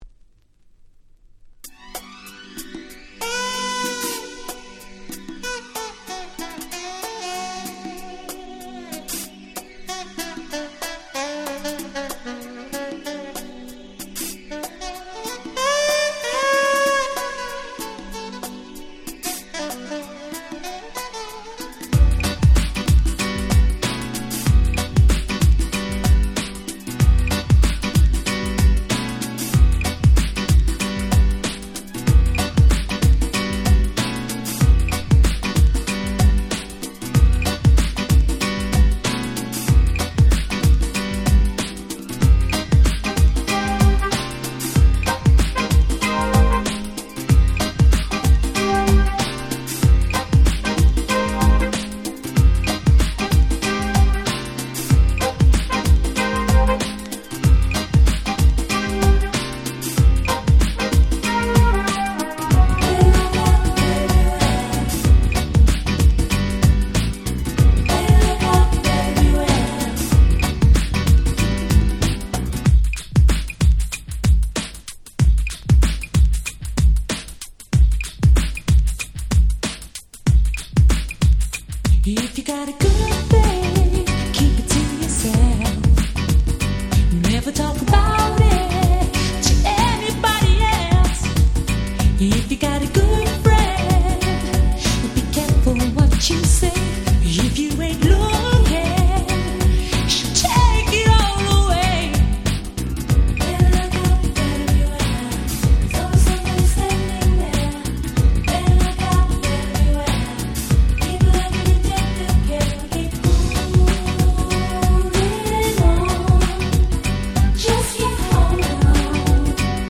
綺麗なPianoのメロディーがもう堪らん！！